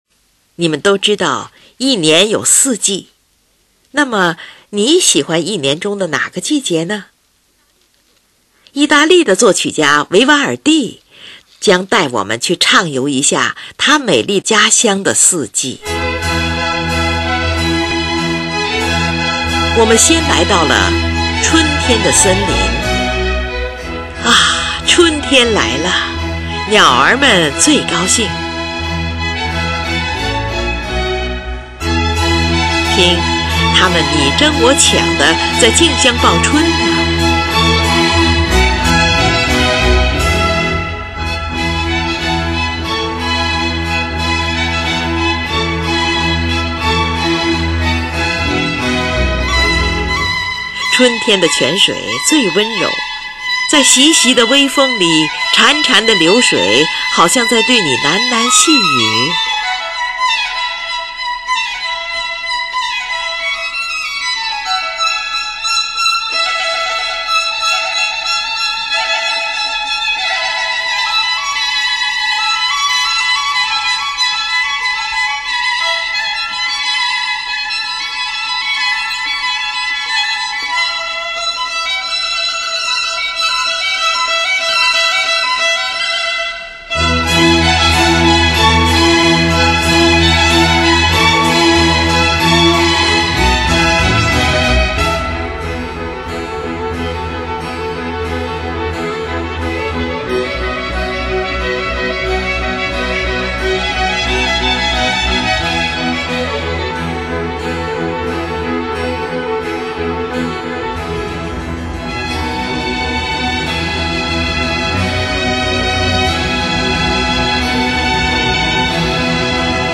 小提琴协奏曲
其中以《春》的第一乐章（快板）最为著名，音乐展开轻快愉悦的旋律，使人联想到春天的葱绿；
春天--E大调
1. 快板，6次合奏中插入5次主奏的复奏形式。
第一次主奏为“鸟之歌”，第一、二小提琴也以合奏形态构成鸟鸣的三声部。
第二次主奏描述泉水在风中潺潺流动。
低音合奏代表雷声，主奏小提琴代表闪电。
合奏与独奏以三重奏表现鸟之歌。